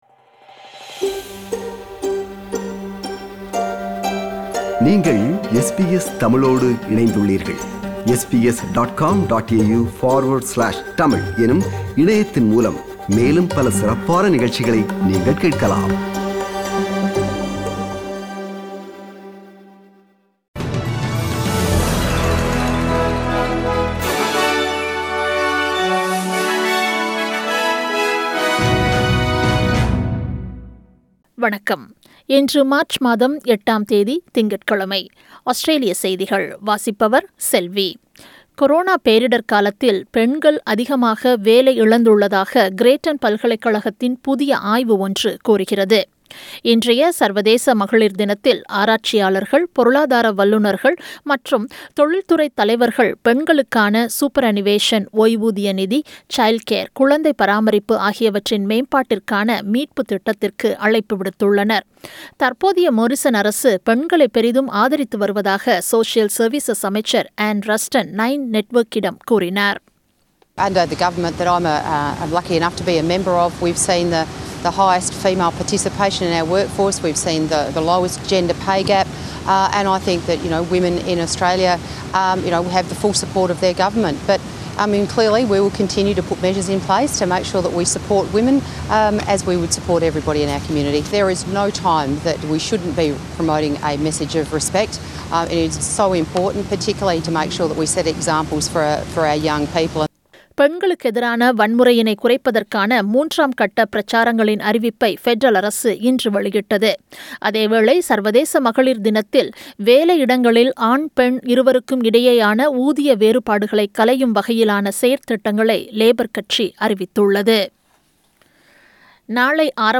Australian news bulletin for Monday 08 March 2021.